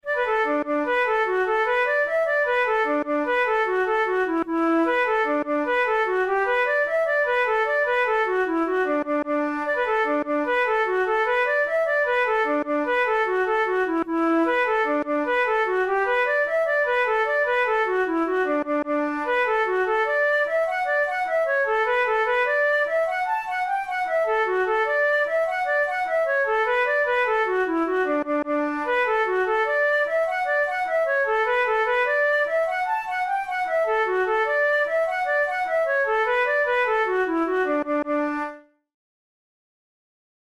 Traditional Irish jig